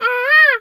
bird_peacock_squawk_07.wav